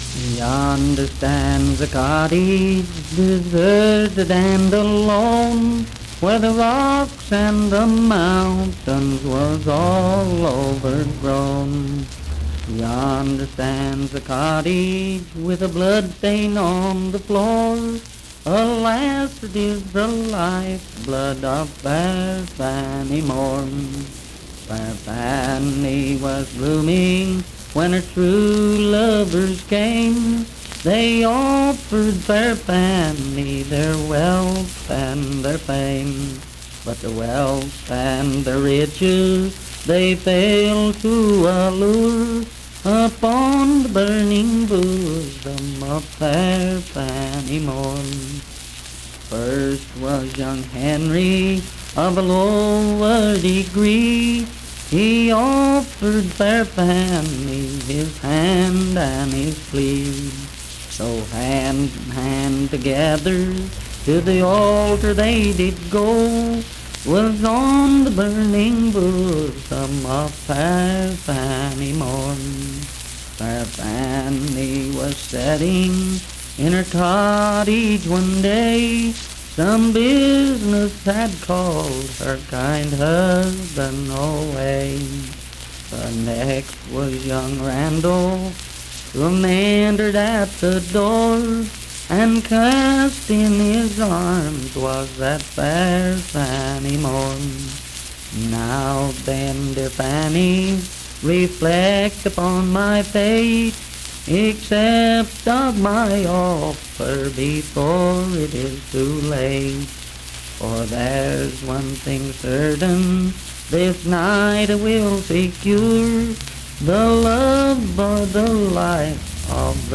Unaccompanied vocal performance
Voice (sung)